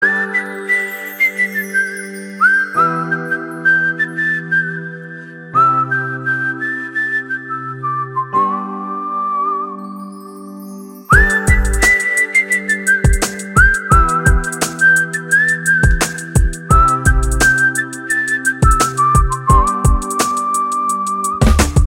File Type : Mp3 ringtones